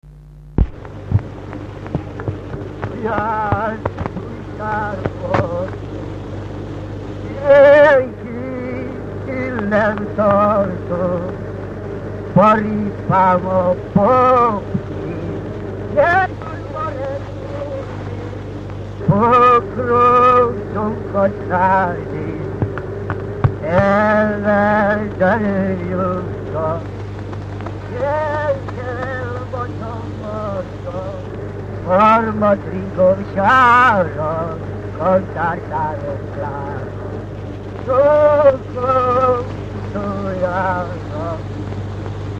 Alföld - Pest-Pilis-Solt-Kiskun vm. - Kecskemét
ének
Stílus: 8. Újszerű kisambitusú dallamok
Szótagszám: 6.6.6+6.6
Kadencia: 1 (2) V 1